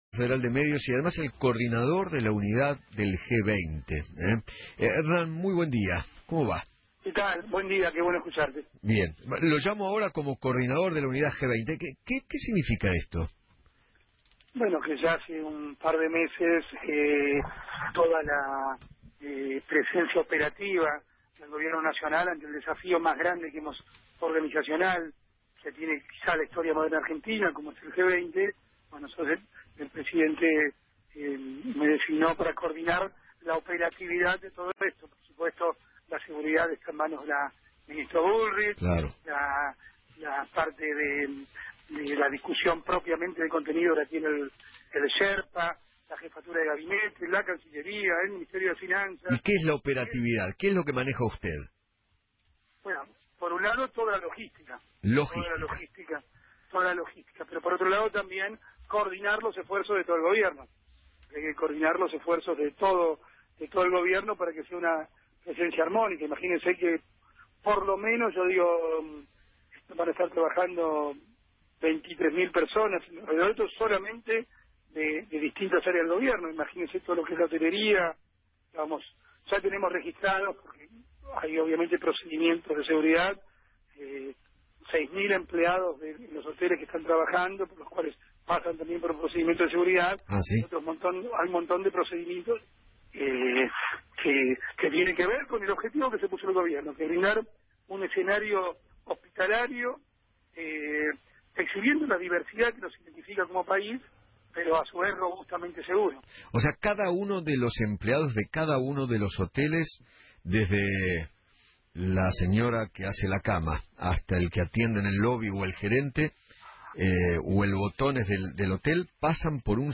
Hernán Lombardi, Titular del Sistema Federal de Medios y Contenidos Públicos de la República Argentina y coordinador de la organización del G20, habló en Feinmann 910 y dijo que  “Hace ya un par de meses, ante el desafío organizacional más grande de la historia moderna del país como es el G20, el Presidente Mauricio Macri me designó para coordinar la operatividad de todo esto”.